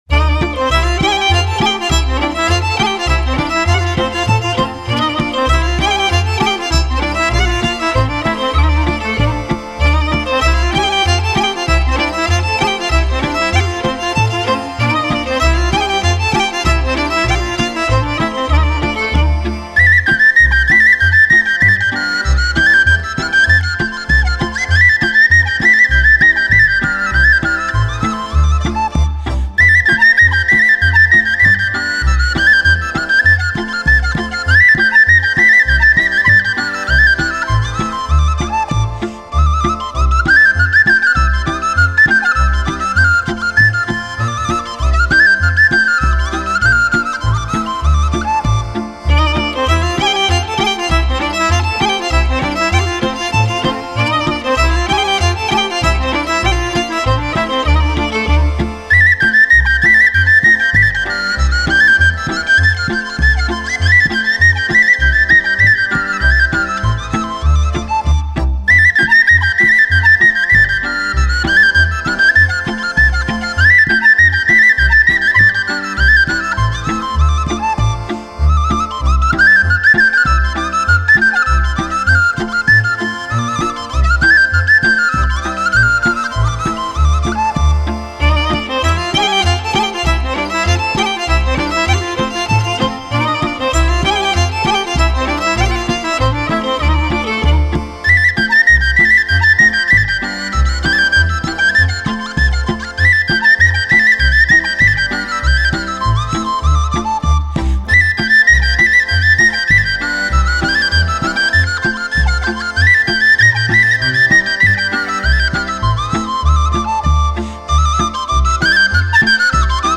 Joc din fluier (2:20)